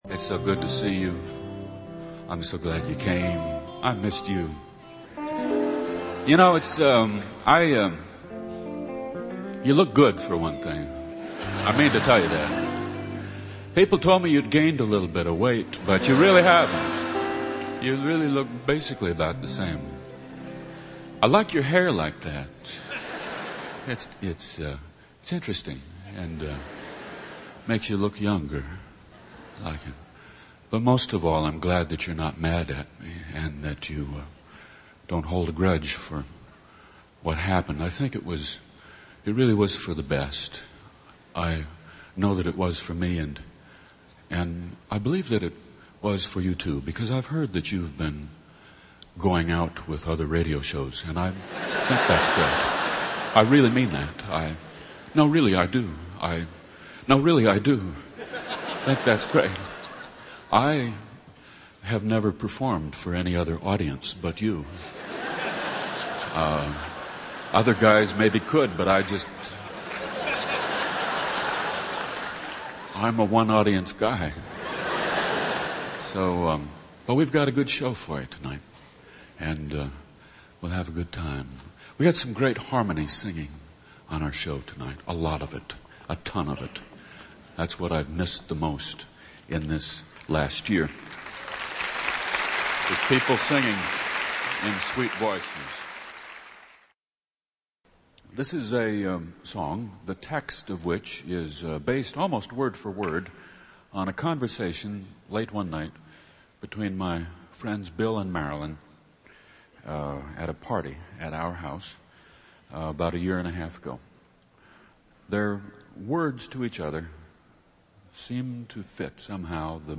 Broadcast live from Radio City Music Hall, the 2nd Farewell (June 4, 1988) was a joyous reunion heard by millions of fans.